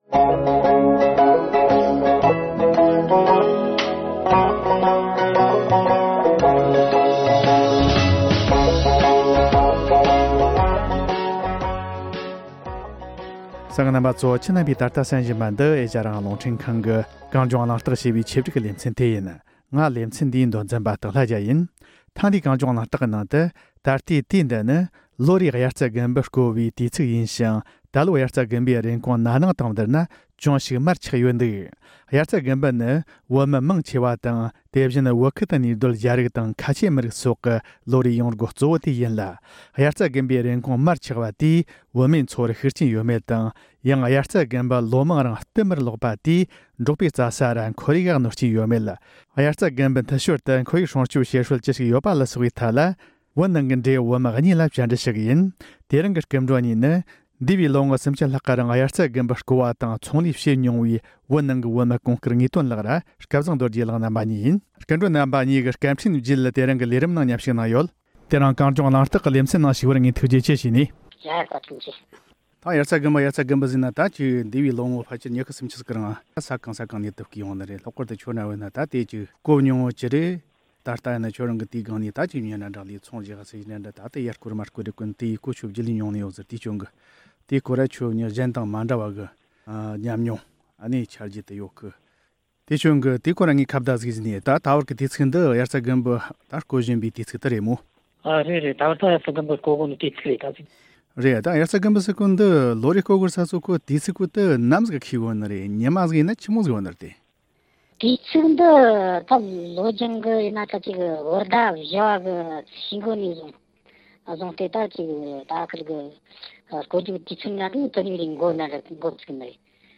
དབྱར་རྩྭ་དགུན་འབུ་འཐུ་ཞོར་དུ་ཁོར་ཡུག་སྲུང་སྐྱོང་བྱེད་སྲོལ་ཅི་ཞིག་ཡོད་པ་ལ་སོགས་པའི་ཐད་བོད་ནང་གི་བོད་མི་གཉིས་ལ་བཅར་འདྲི།